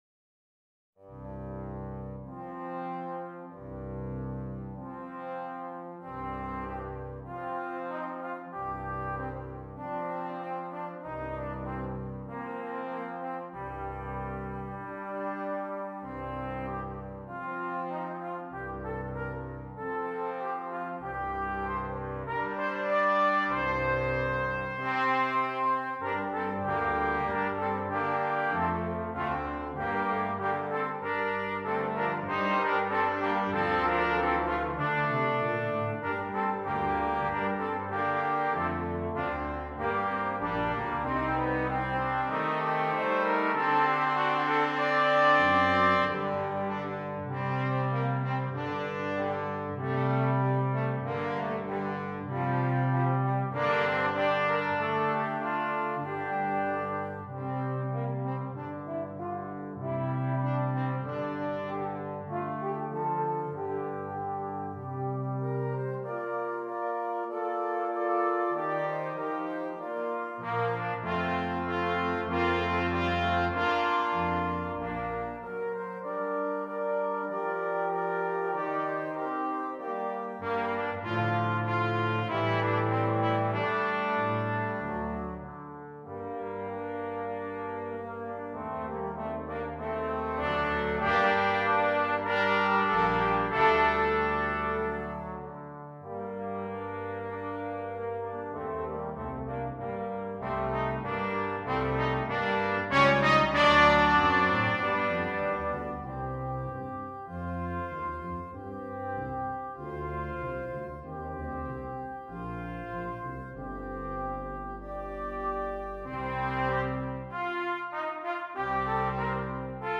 Brass Quintet
A pavane was a slow dance form the 16th and 17th century.